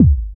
BD BD052.wav